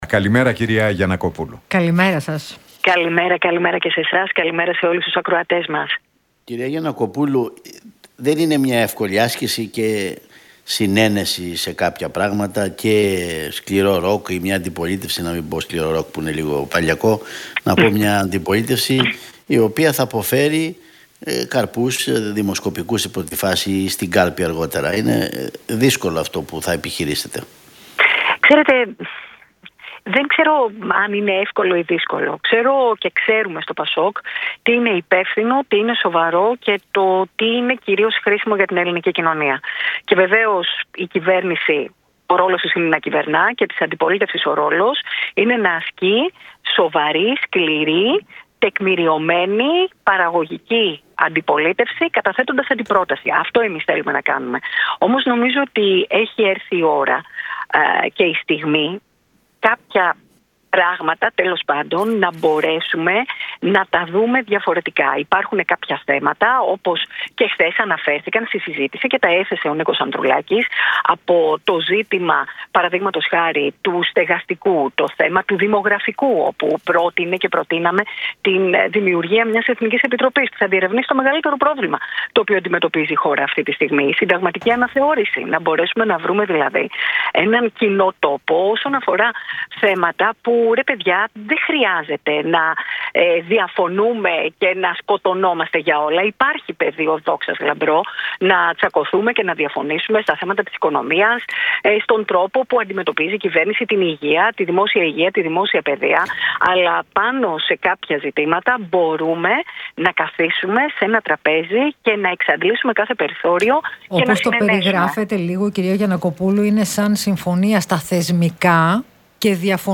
Γιαννακοπούλου στον Realfm 97,8: Δεν μπαίνουμε σε καμία ονοματολογία για Πρόεδρο της Δημοκρατίας